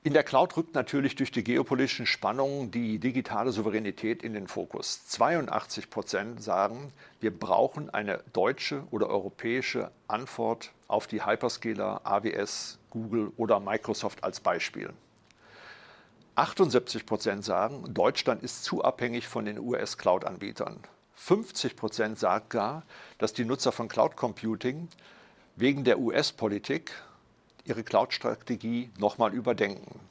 Mitschnitte der Pressekonferenz
bitkom-pressekonferenz-cloud-report-2025-audio-mitschnitt-cloud-anbieter.mp3